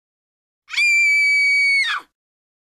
دانلود صدای جیغ زن ترسناک 1 از ساعد نیوز با لینک مستقیم و کیفیت بالا
جلوه های صوتی